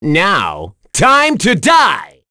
Mitra-Vox_Skill2_c.wav